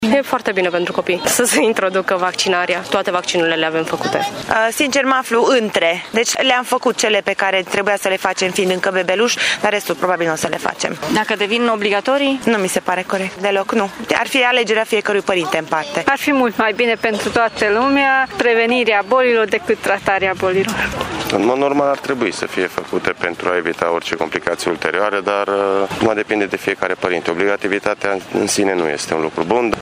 Acesta nu este, însă, un motiv suficient de întemeiat pentru a obliga părinții să-și vaccineze copiii, spun unii părinții intervievați de reporterul RTM, mai ales că rata vaccinării se apropie de 90% în țara noastră: